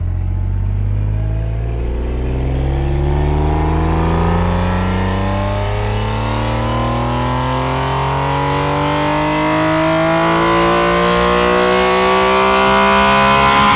rr3-assets/files/.depot/audio/Vehicles/f6_01/f6_01_Accel.wav
f6_01_Accel.wav